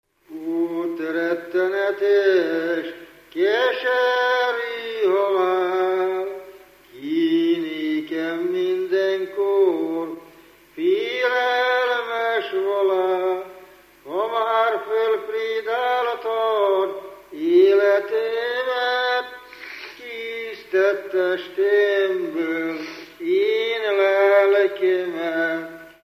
Moldva és Bukovina - Bukovina - Hadikfalva
Dallamtípus: Lóbúcsúztató - halottas 2; Hol vagy, én szerelmes Jézus Krisztusom
Stílus: 8. Újszerű kisambitusú dallamok